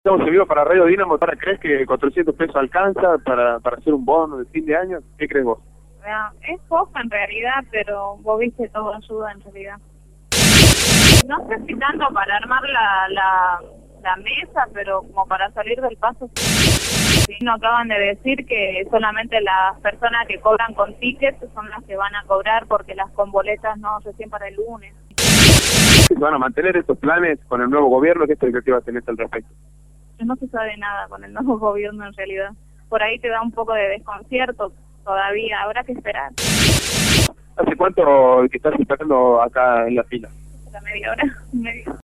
BENEFICIARIA-DEL-BONO-DE-400-PESOS.mp3